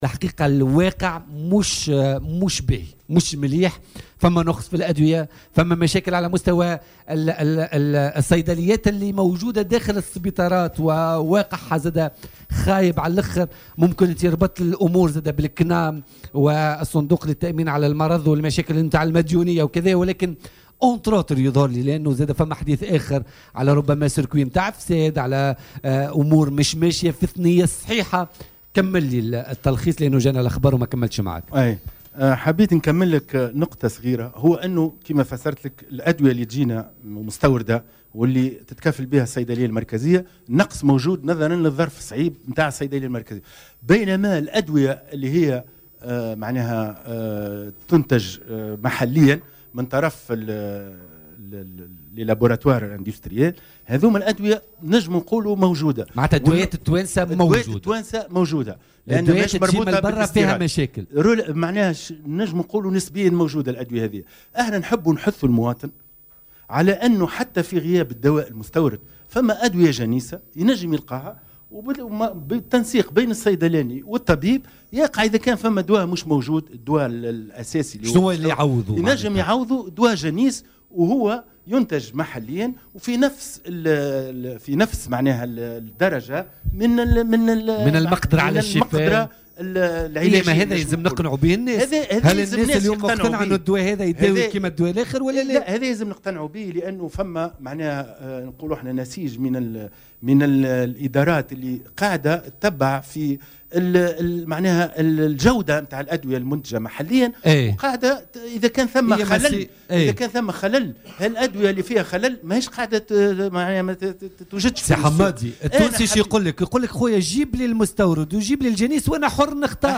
وأوضح في مداخلة له اليوم في برنامج "بوليتيكا" أن هناك نقصا في الأدوية المستوردة التي تتكفل بها الصيدلية المركزية، داعيا إلى ضرورة إيجاد حلول و التوجه إلى استعمال الأدوية المنتجة محليا.